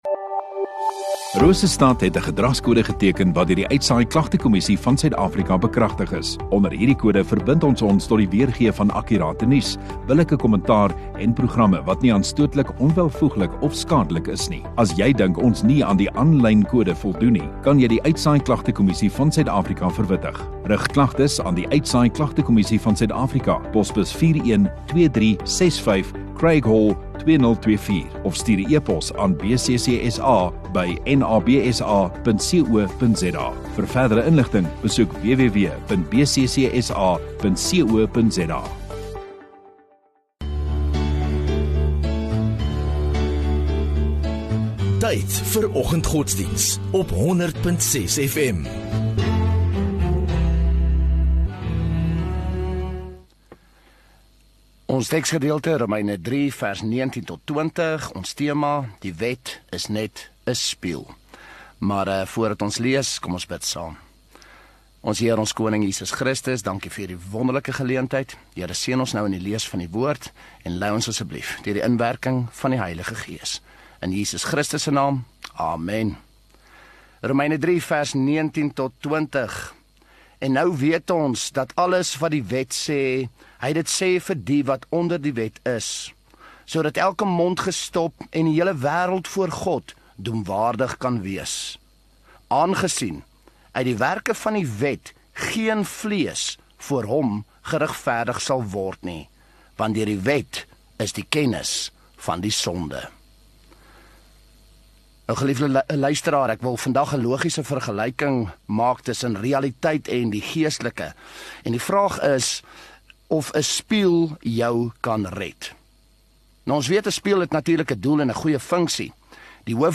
17 Mar Maandag Oggenddiens